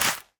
Minecraft Version Minecraft Version latest Latest Release | Latest Snapshot latest / assets / minecraft / sounds / block / sweet_berry_bush / place3.ogg Compare With Compare With Latest Release | Latest Snapshot